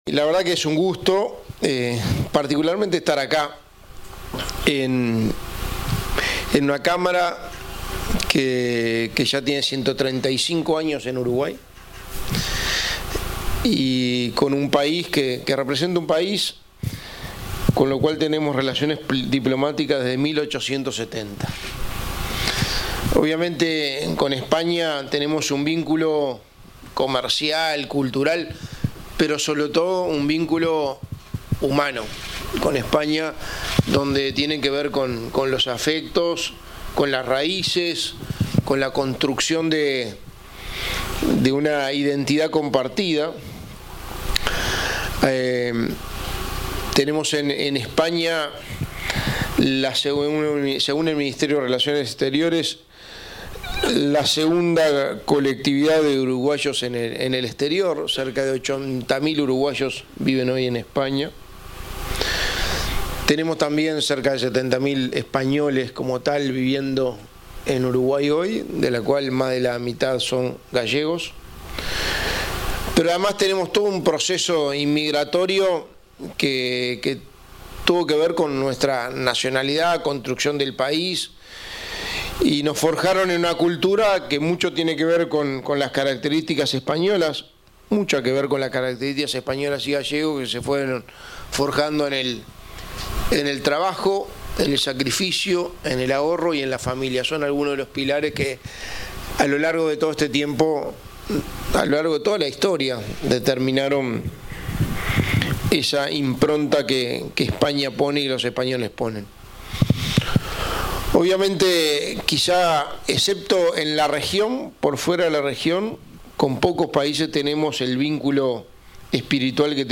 Disertación del secretario de Presidencia, Álvaro Delgado
Disertación del secretario de Presidencia, Álvaro Delgado 24/08/2023 Compartir Facebook X Copiar enlace WhatsApp LinkedIn Este jueves 24 en Montevideo, el secretario de la Presidencia de la República, Álvaro Delgado, disertó en un desayuno de consulta organizado por la Cámara de Comercio Española.